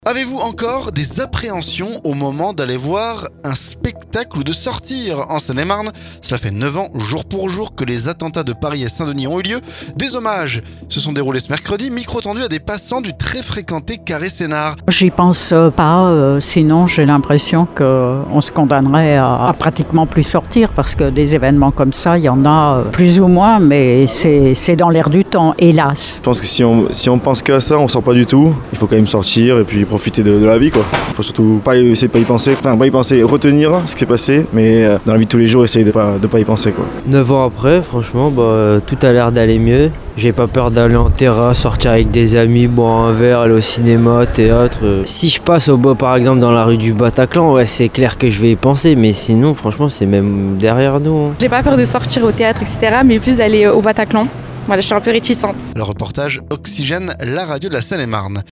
Micro tendu à des passants du très fréquenté Carré Sénart.